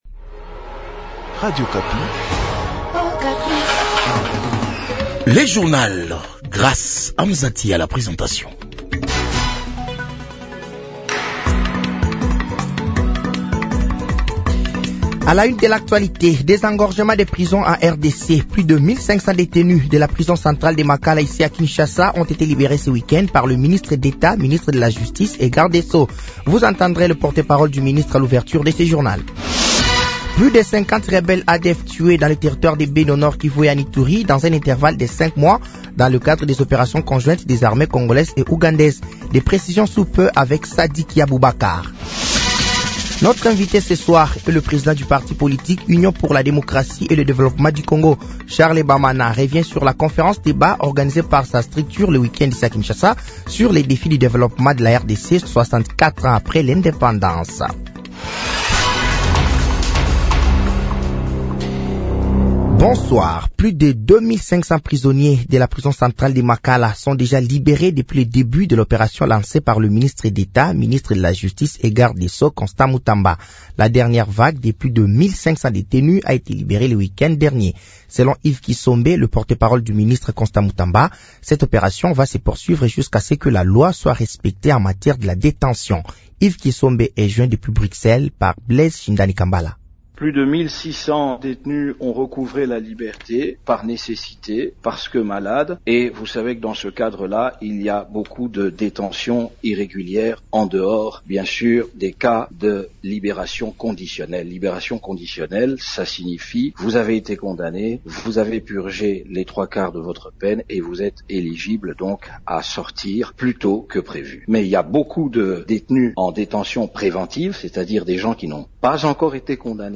Journal français de 18h de ce lundi 23 septembre 2024